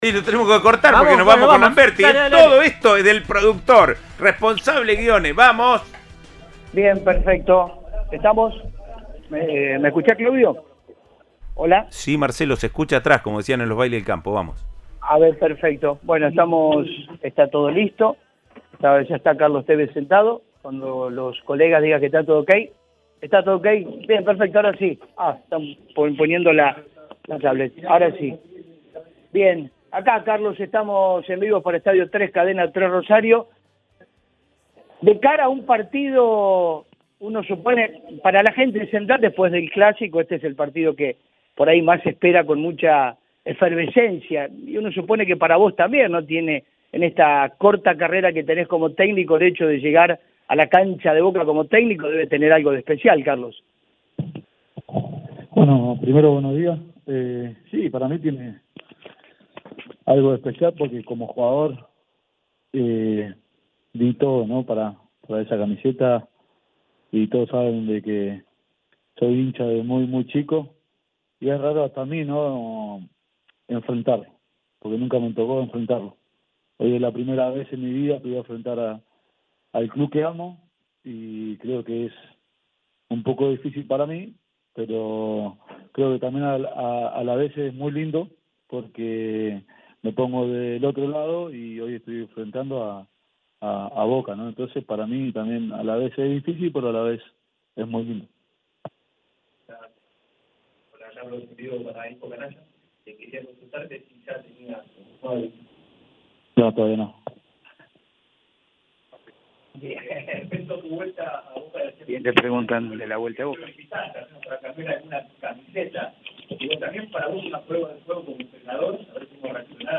El entrenador de Rosario Central, Carlos Tevez brindó una conferencia en la previa del partido con Boca Juniors. El Apache se mostró feliz por el buen presente de los pibes de Rosario Central.